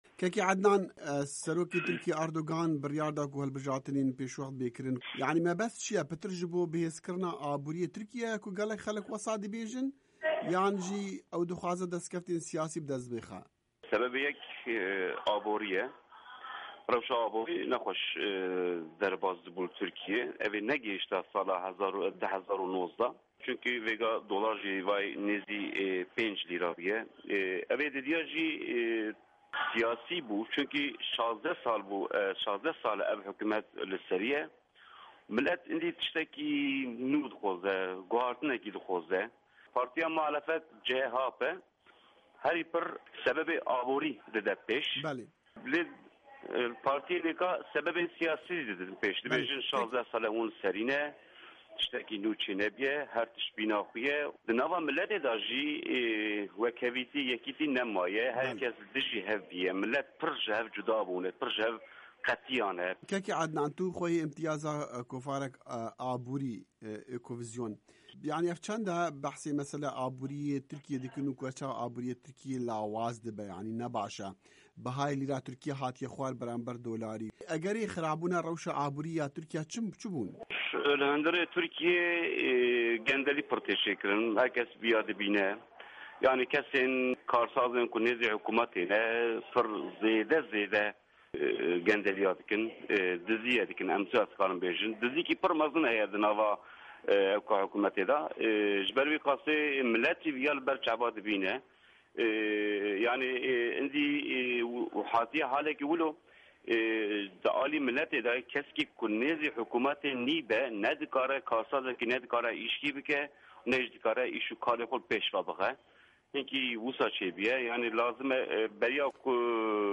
Di hevpeyvînekê de bi Dengê Amerîka VOA re